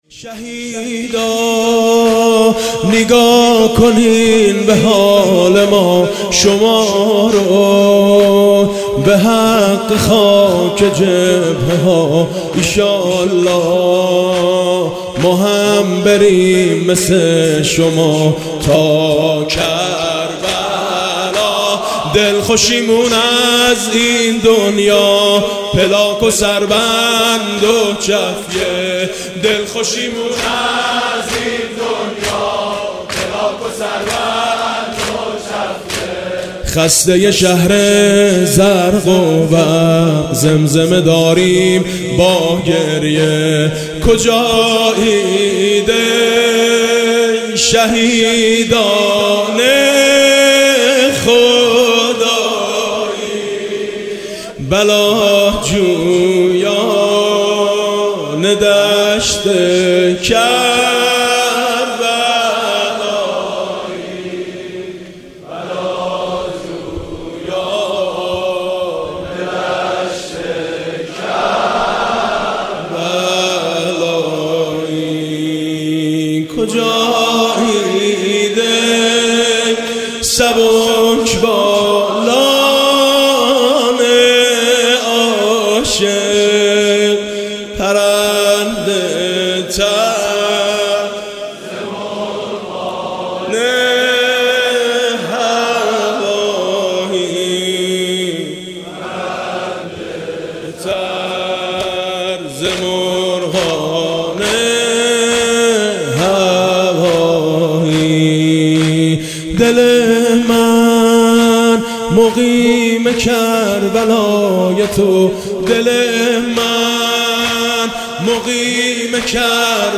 مداحی زیبا حاج میثم مطیعی در رثای شهدا.